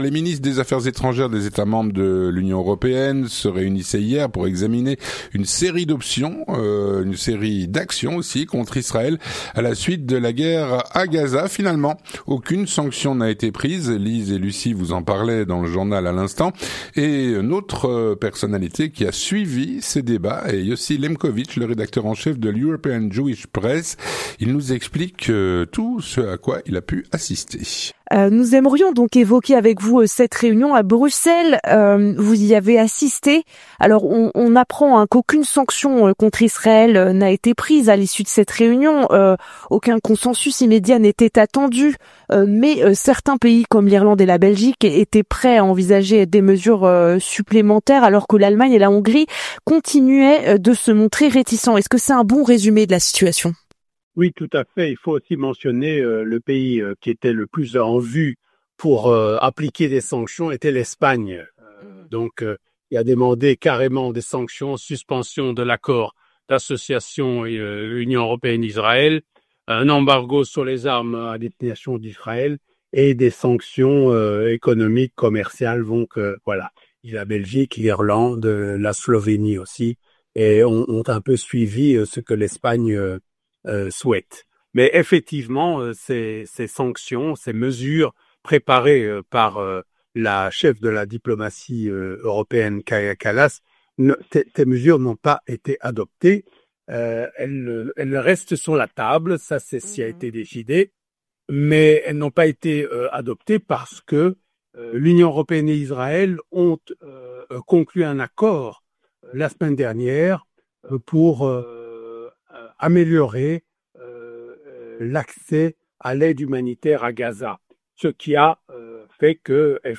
L'entretien du 18H - Les ministres des affaires étrangères de l’UE se réunissaient hier pour examiner une série d'actions contre Israël.